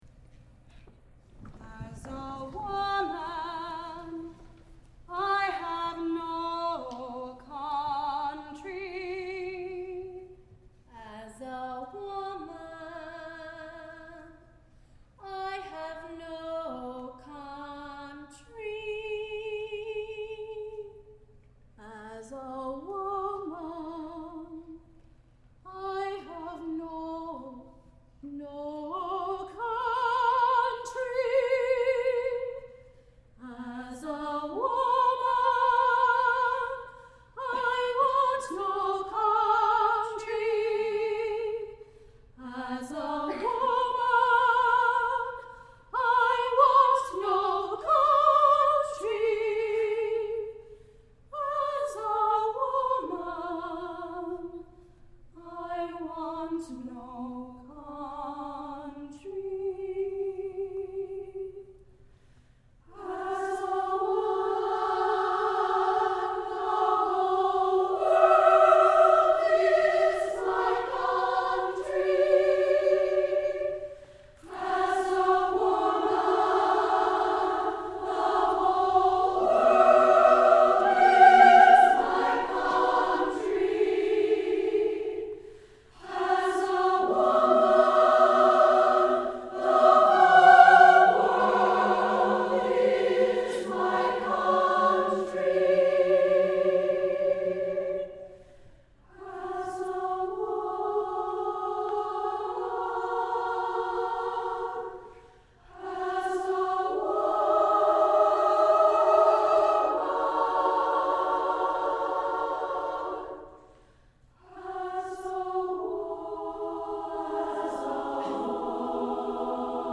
SSA, a cappella